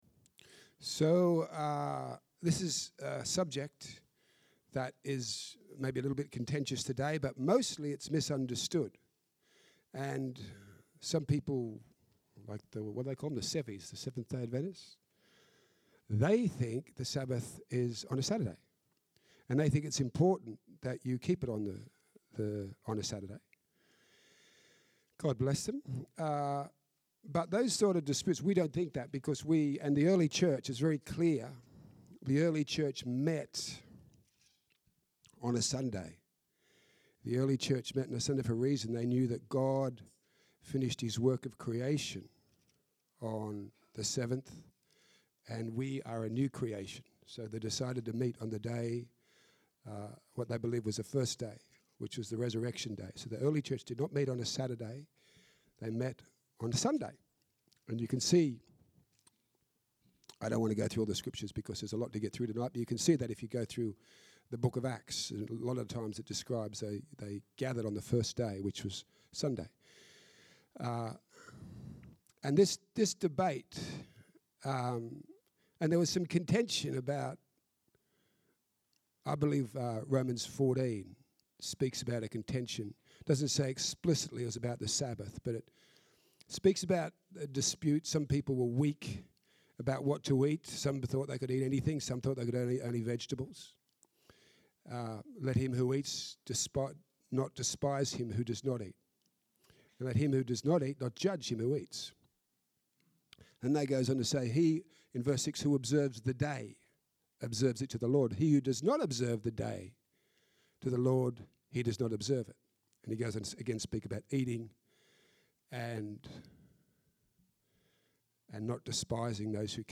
Ten Commandments night service upload.mp3